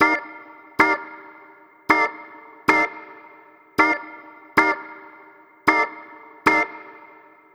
Swingerz 2 Organ-C.wav